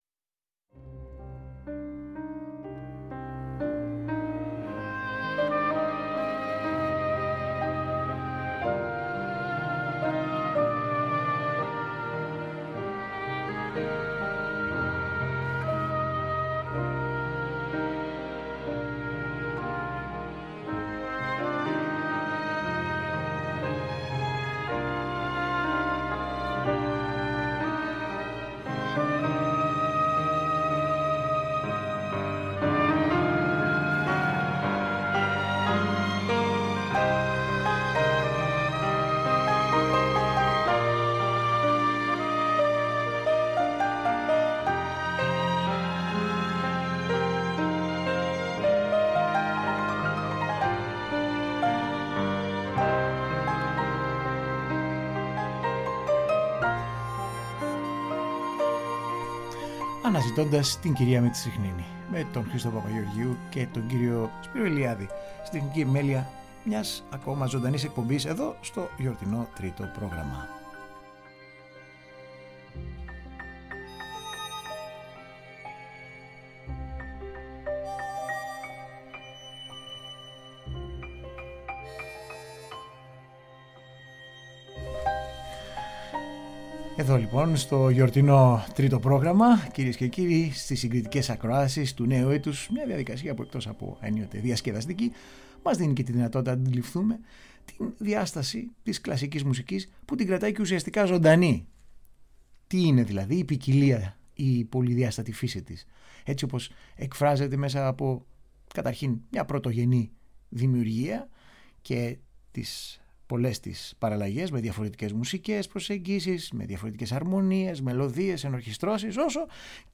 Συγκριτικές ακροάσεις Ο «Ιανουάριος» του Τσαϊκόφσκυ και άλλες επίκαιρες μουσικές σε ανεπανάληπτες εκδοχές και ερμηνείες.